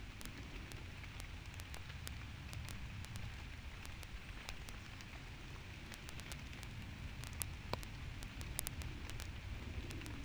Listen to the left channel below: the clicks aren’t rain; compare them to the right channel.
▶ Sound of MEMS with beginnings of corrosion on the L channel
8_corrosion-start.wav